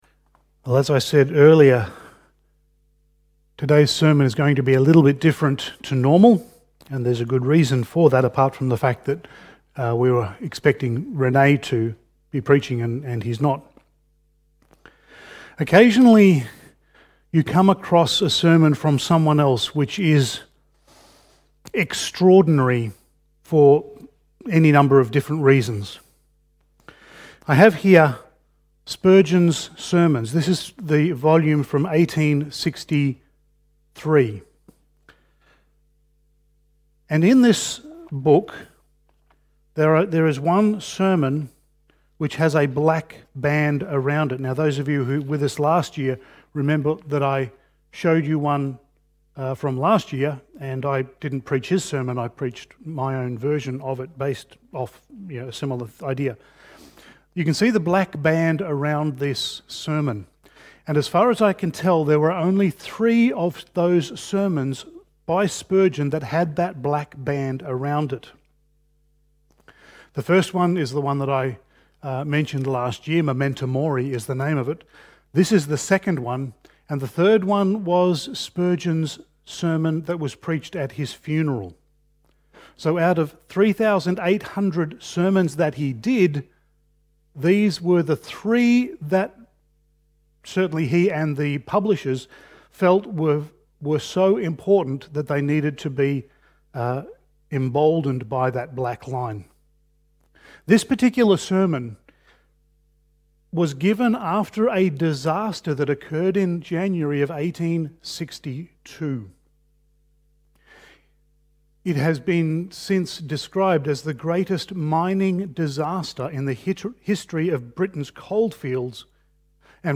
Series: Topical Sermon Passage: Job 14:14 Service Type: Sunday Morning